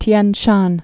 (tyĕn shän)